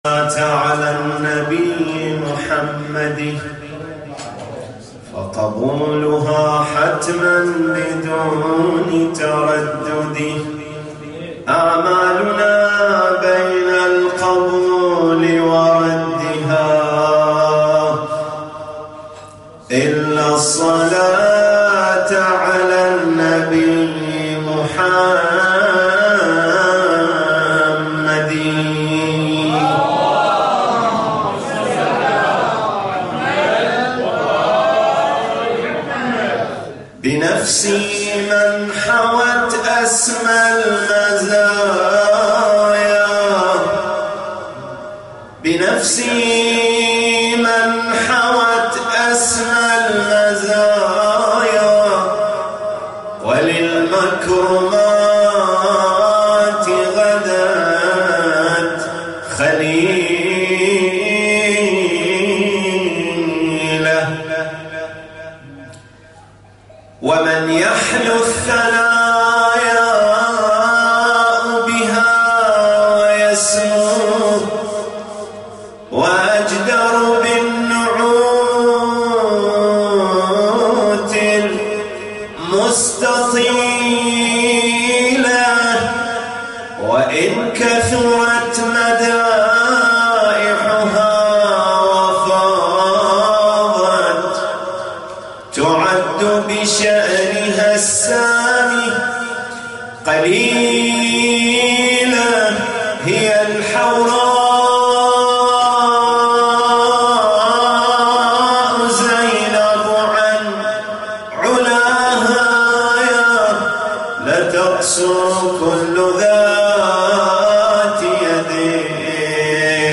تغطية صوتية: مولد السيدة زينب “ع” 1439هـ